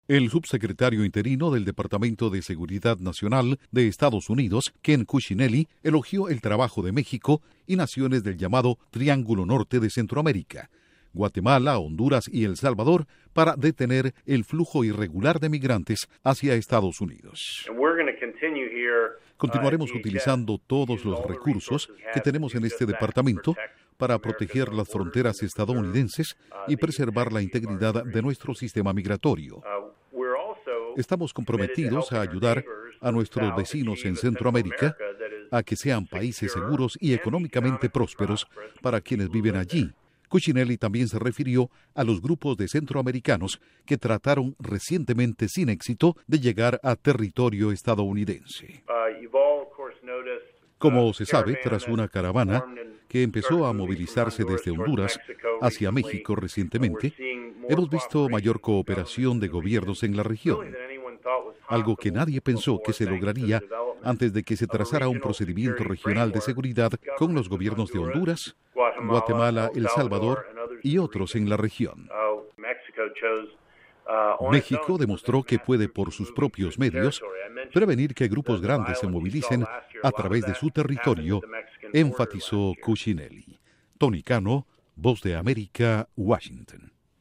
EE.UU. elogia esfuerzo de México y naciones centroamericanas para detener inmigración irregular. Informa desde la Voz de América en Washington
Duración: 1:27 Con declaraciones de Ken Cuccinelli/Departamento Seguridad Nacional